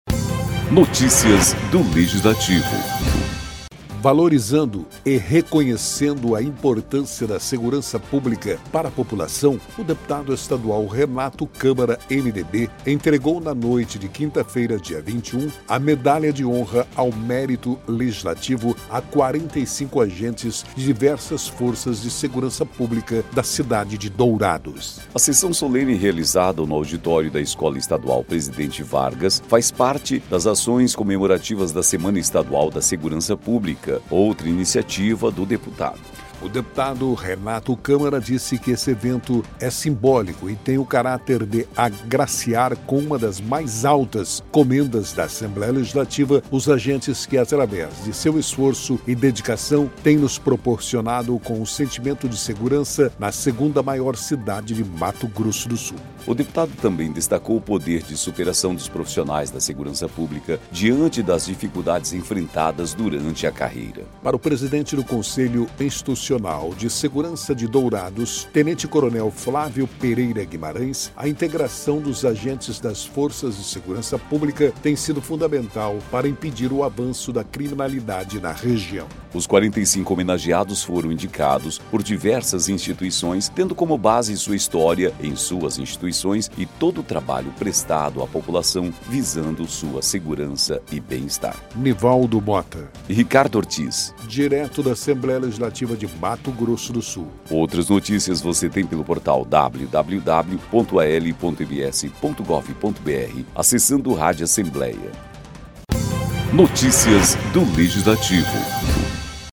Em sessão solene, 45 agentes das forças de segurança são homenageados em Dourados